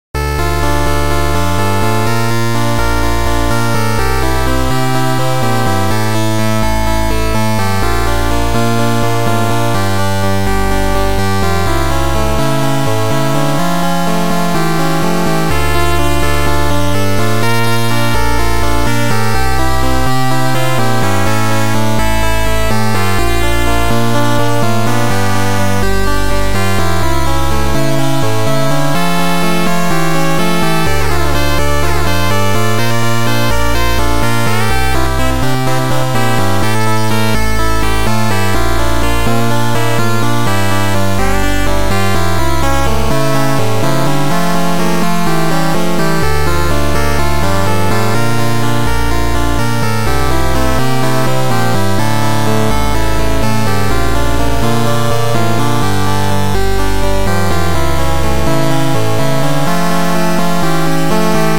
Sound Format: Noisetracker/Protracker
Chip Music